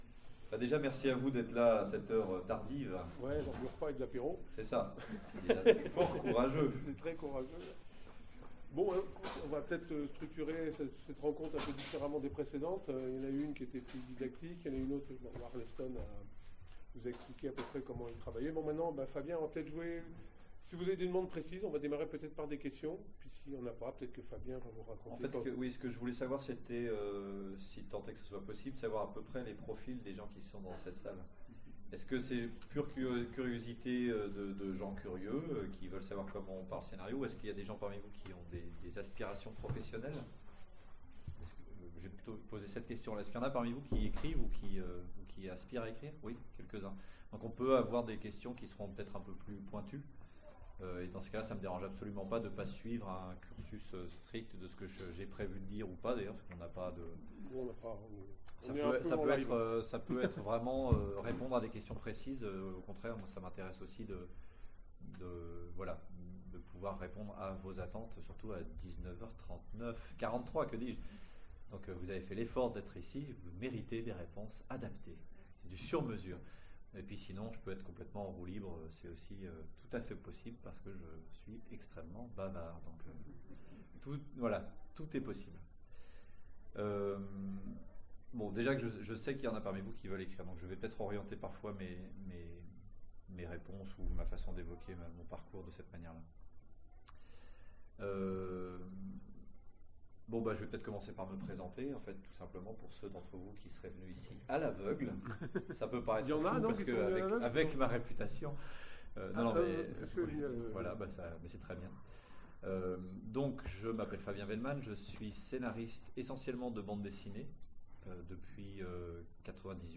Utopiales 13 : Cours du soir avec Fabien Vehlmann